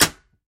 Звуки короткого замыкания
На этой странице собраны звуки короткого замыкания — резкие, трещащие и искрящие аудиоэффекты.
Звук выбитых электрических пробок